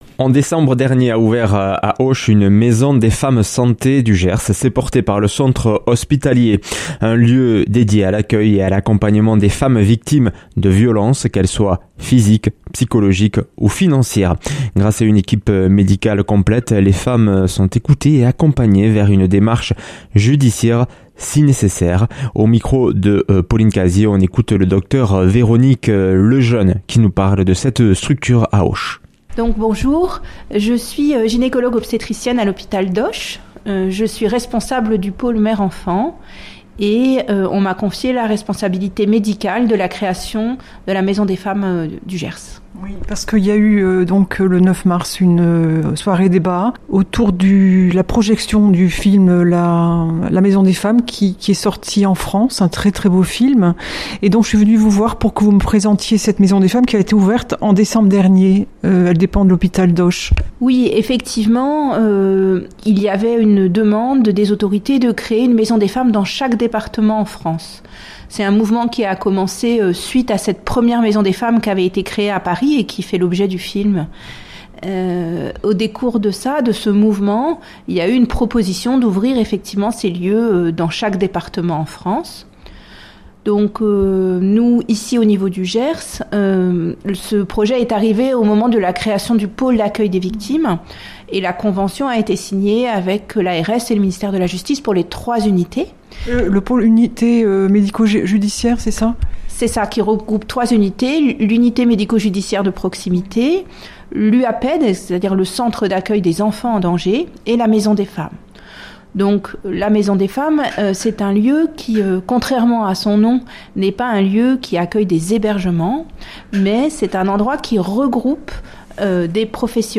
Accueil \ Emissions \ Information \ Locale \ Interview et reportage \ A Auch, lieu d’accueil et d’accompagnement des femmes victimes de violences.